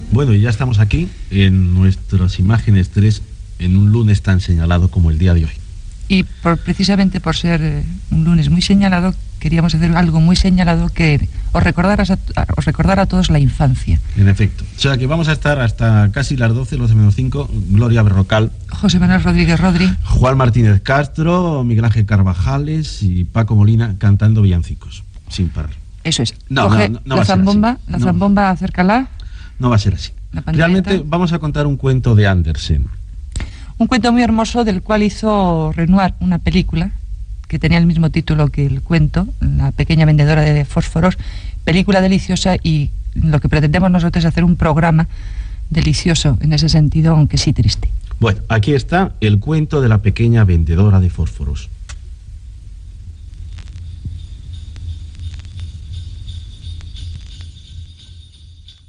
Presentació del programa de la nit de Nadal
Entreteniment